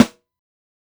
SNARE_OUTLAWZ.wav